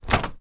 На этой странице собраны реалистичные звуки пластиковых дверей: от плавного открывания до резкого захлопывания.
Дергаем за ручку перед открытием пластиковой двери